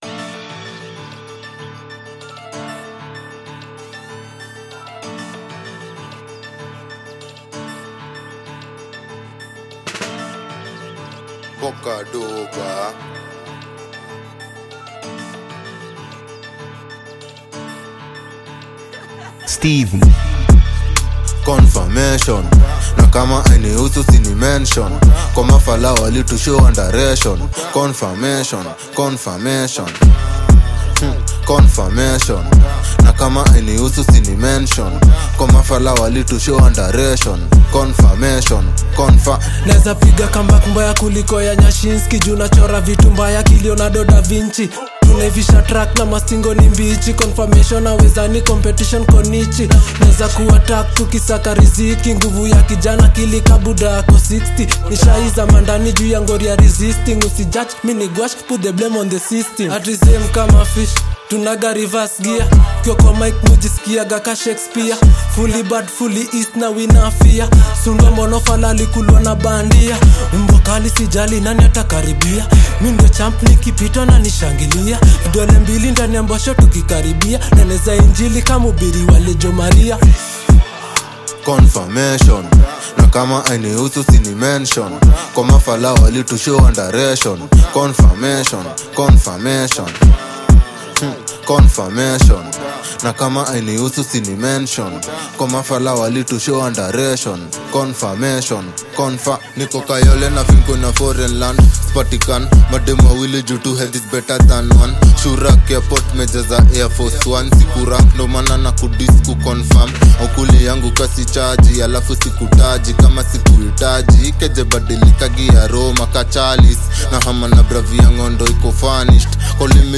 With their seamless chemistry and bold vibe